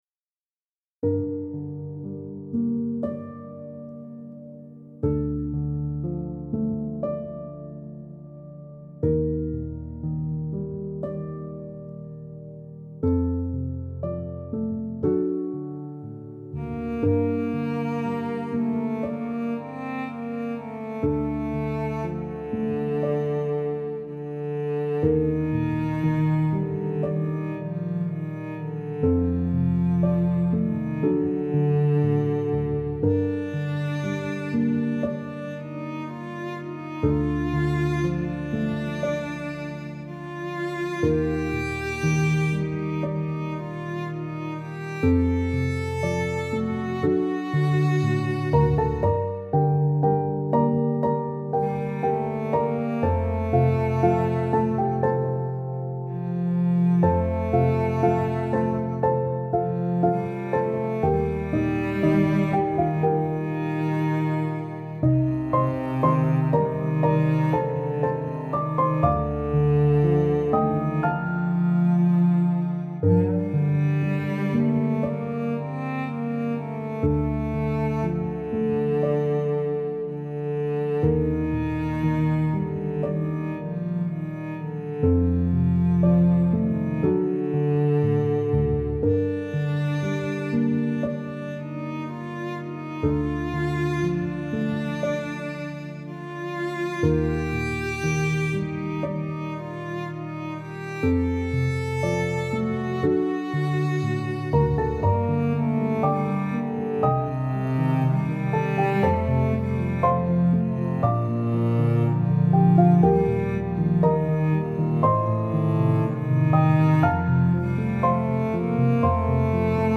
سبک پیانو , غم‌انگیز , موسیقی بی کلام
موسیقی بی کلام نئو کلاسیک